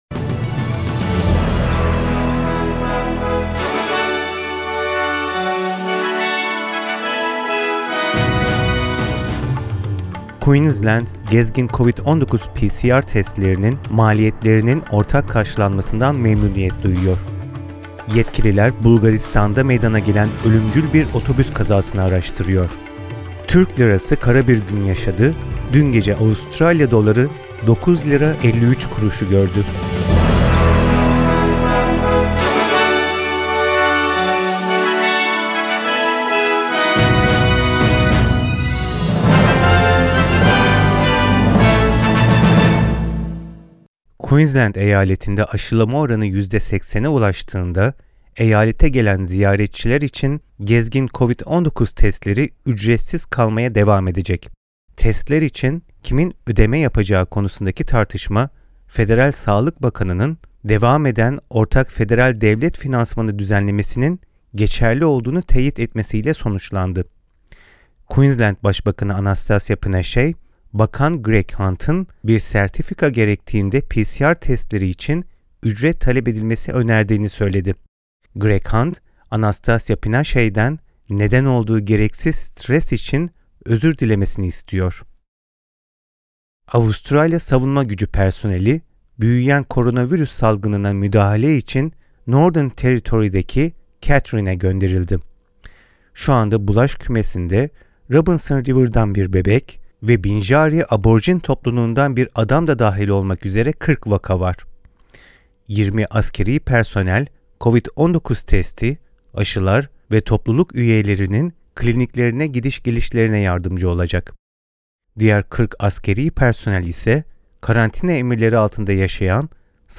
SBS Türkçe Haberler 24 Kasım